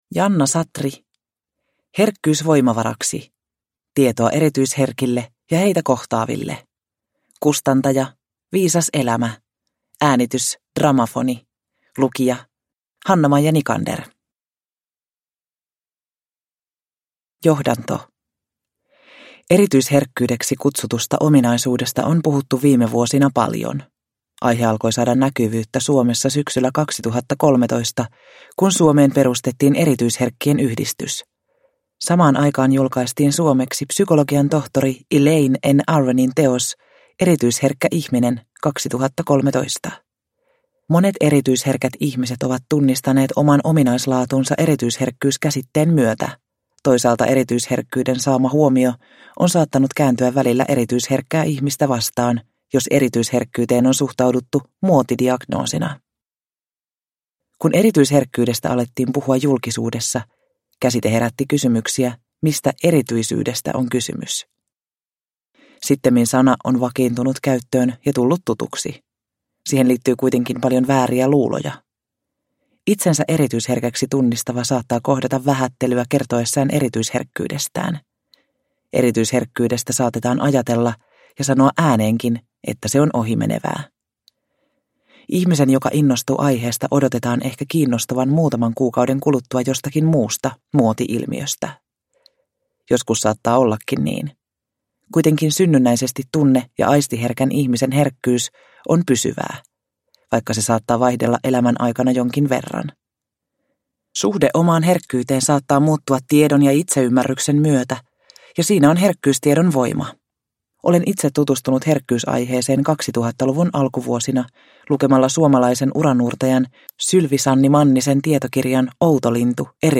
Herkkyys voimavaraksi – Ljudbok – Laddas ner